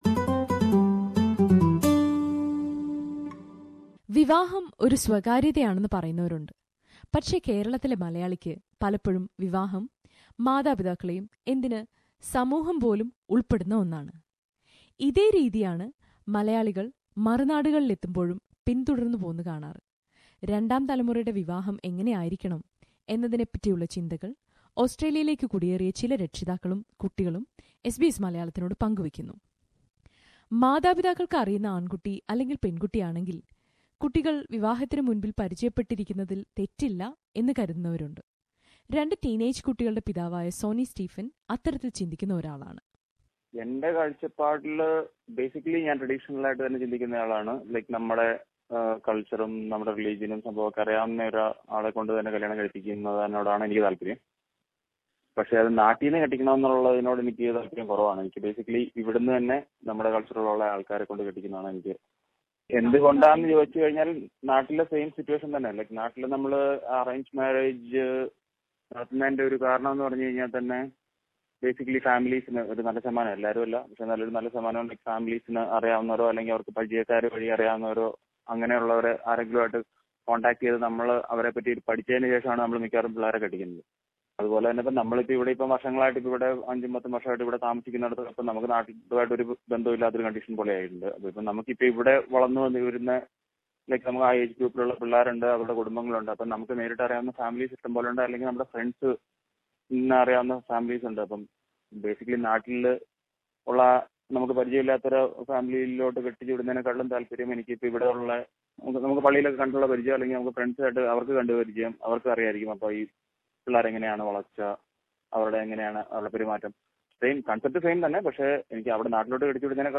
ഓസ്ട്രേലിയൻ മലയാളികൾ നേരിടുന്ന സാംസ്കാരികമായ ആശയക്കുഴപ്പങ്ങളെക്കുറിച്ചുള്ള പരന്പരയുടെ രണ്ടാം ഭാഗത്തിൽ, വിവാഹം എന്ന വിഷയമാണ് എസ് ബി എസ് മലയാളം പരിശോധിക്കുന്നത്. വിവാഹത്തെക്കുറിച്ചുള്ള സങ്കൽപ്പം ഓസ്ട്രേലിയയിലും ഇന്ത്യയിലും തിർത്തും വ്യത്യസ്തമാണ്. ഓസ്ട്രേലിയയിൽ വളർന്നുവരുന്ന പുതുതലമുറ മലയാളികൾ ഇതിൽ ഏതു സങ്കൽപ്പമാണ് പിന്തുടരേണ്ടത്? ചർച്ച കേൾക്കാം, മുകളിലെ പ്ലേയറിൽ നിന്ന്...